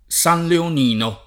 San leon&no] top. (Tosc.) — oggi preval. la forma -lino per S. L. in val d’Ambra e altri luoghi, la forma -nino per S. L. in val d’Elsa — sim. i cogn. Sanleolini, Sanleonini